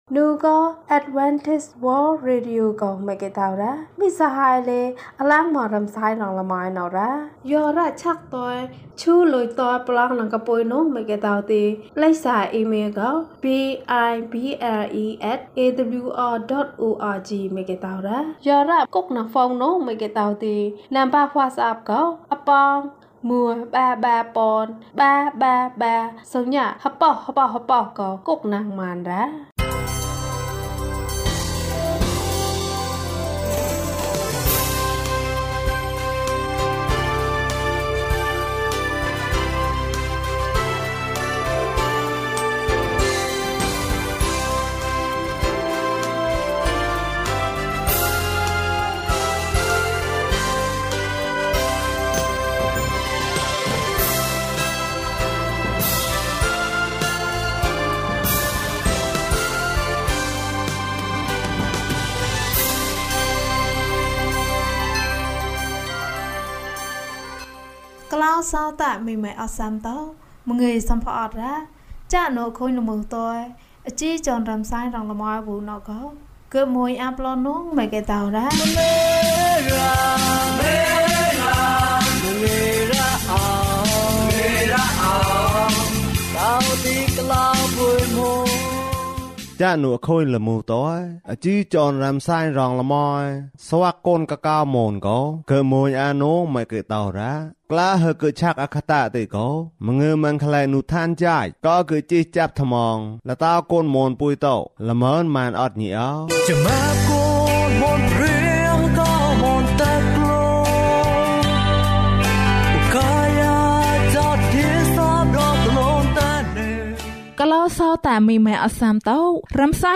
ခရစ်တော်ထံသို့ ခြေလှမ်း။၃၉ ကျန်းမာခြင်းအကြောင်းအရာ။ ဓမ္မသီချင်း။ တရားဒေသနာ။